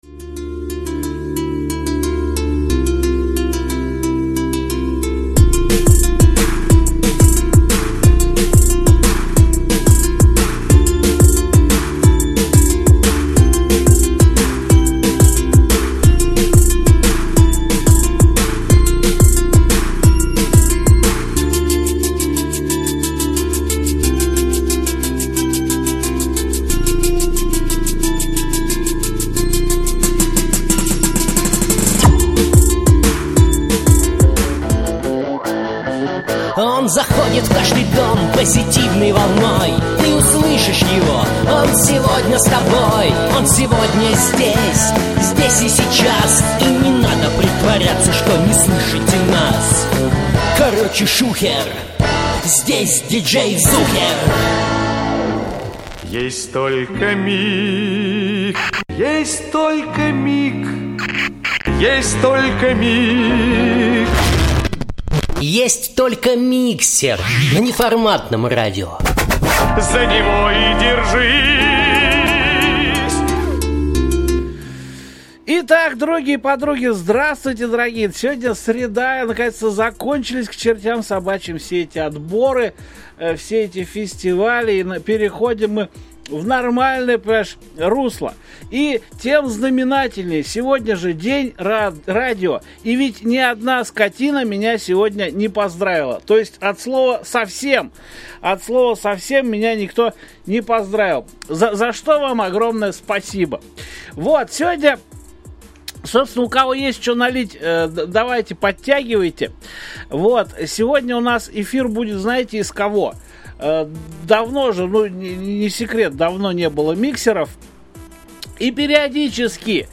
рок-группа